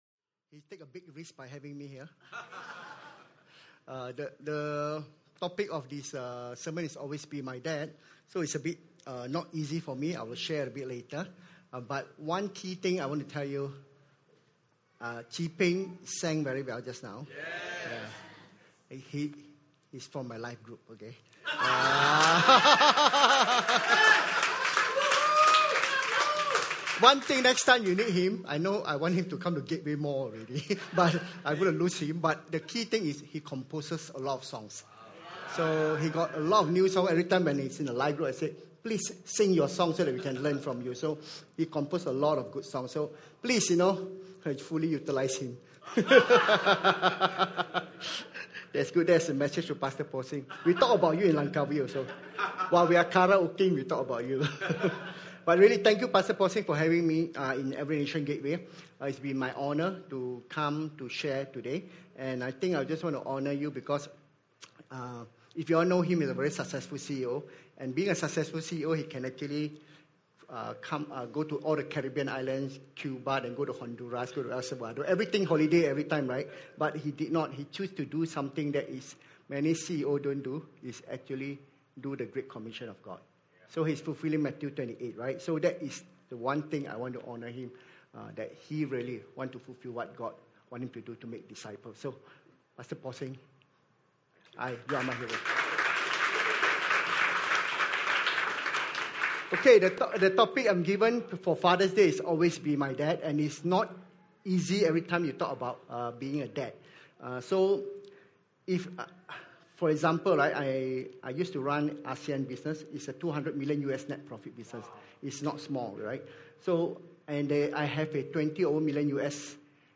Always be my Dad - Father's day Celebration - ENCM